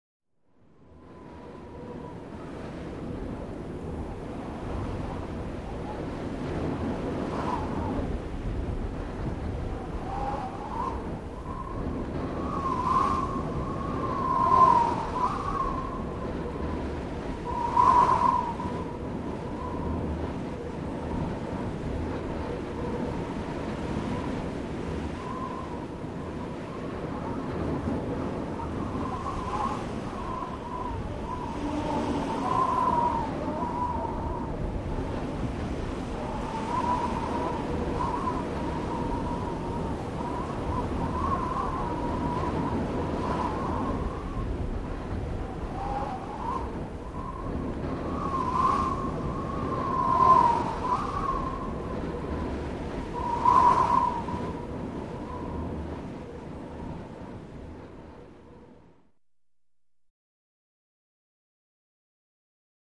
GustsHeavyColdWind PE031601
Gusts; Heavy Cold Wind, Howling Or Whistling With Swirling.